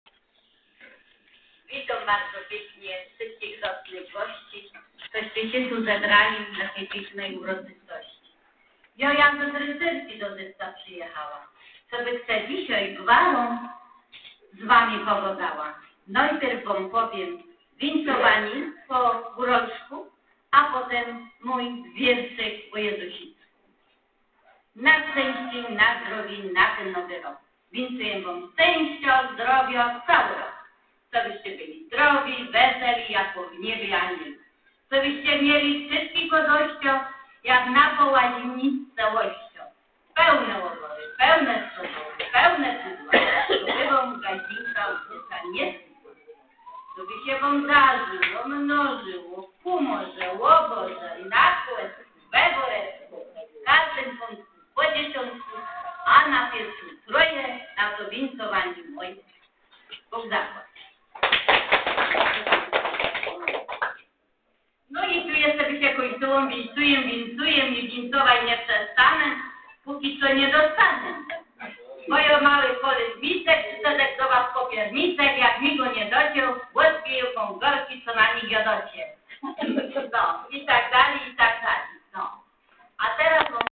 Oto wybrane fragmenty spotkania (przepraszamy za usterki w nagraniach) oraz teksty kolęd do ew. pobrania i foto-galeria.